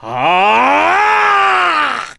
ssbm_ganondorf_chargeattack.mp3